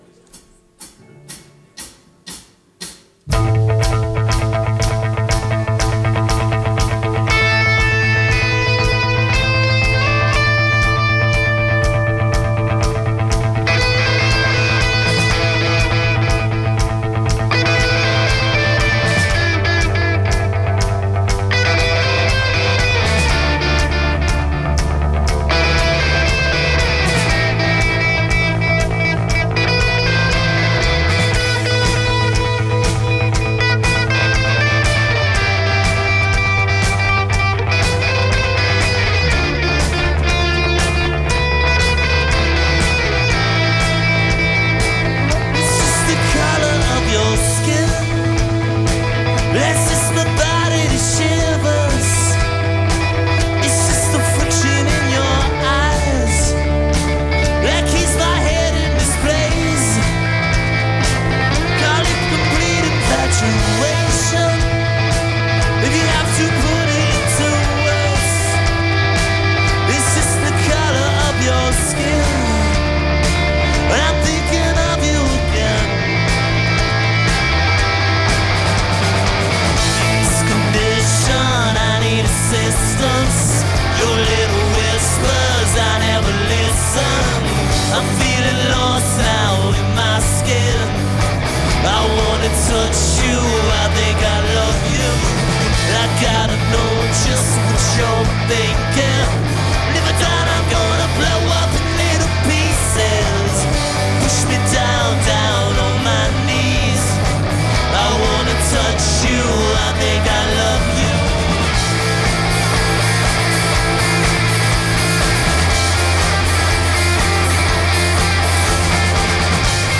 enregistrée le 17/03/2008  au Studio 105